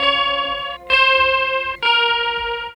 60 GUIT 1 -R.wav